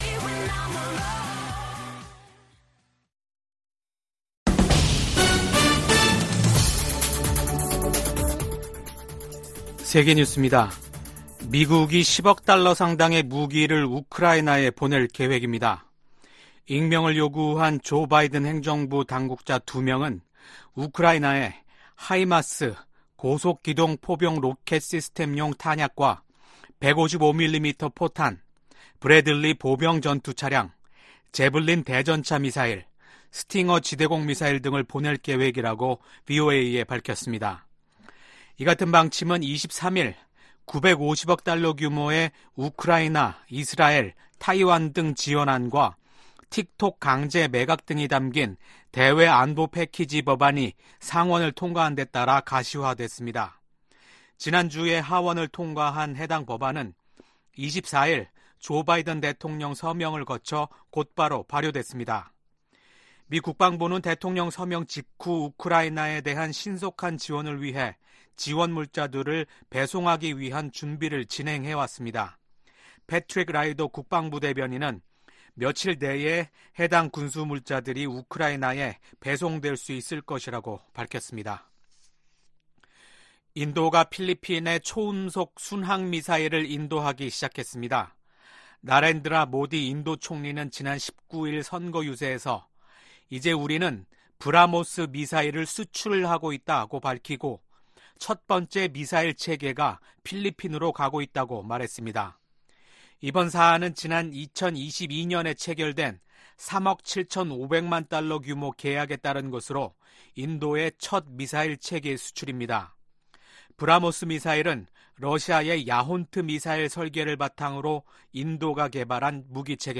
VOA 한국어 아침 뉴스 프로그램 '워싱턴 뉴스 광장' 2024년 4월 25일 방송입니다. 미 국무부는 북한의 핵반격훈련 주장에 무책임한 행동을 중단하고 진지한 외교에 나서라고 촉구했습니다. 미 국방부는 역내 안보를 위해 한국, 일본과 긴밀히 협의하고 있다고 밝혔습니다. 북한의 김여정 노동당 부부장은 올들어 이뤄진 미한 연합훈련 모두를 비난하며 핵 무력을 계속 비축하겠다고 밝혔습니다.